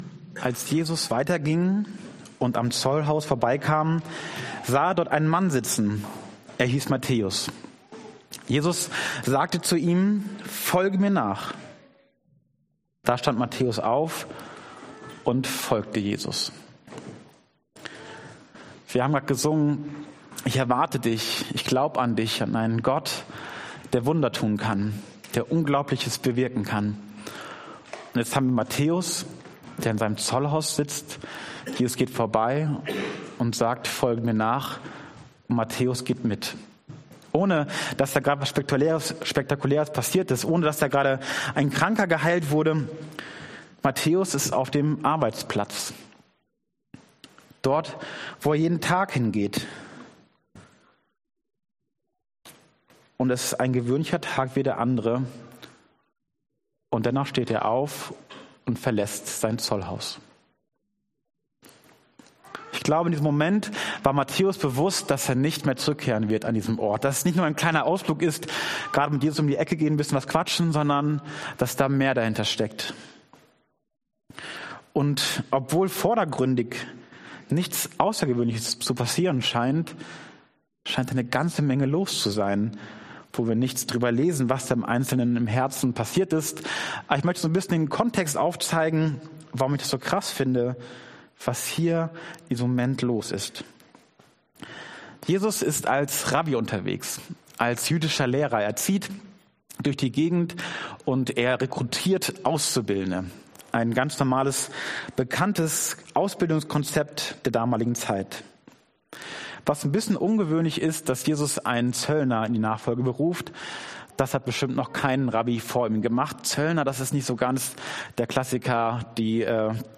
Trinität Passage: Matthäus 9,9-13 Dienstart: Predigt « Koinonia